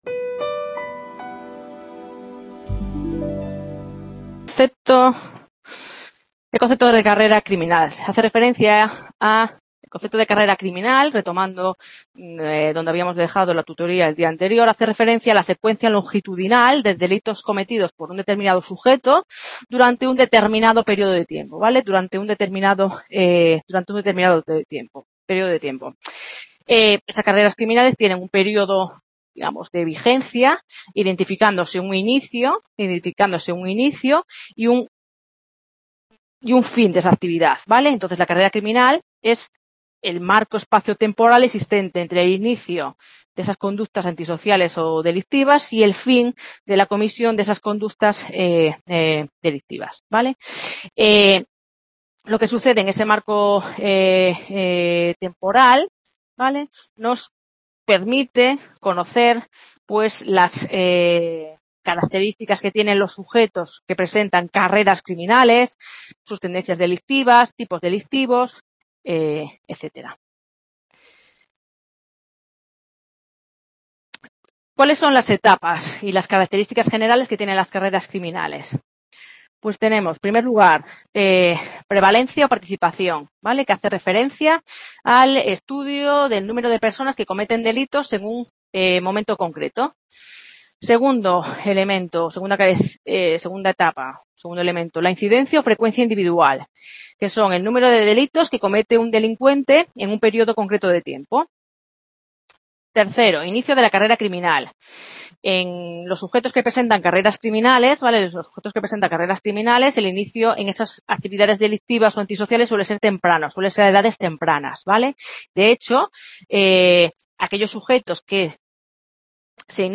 Lección 3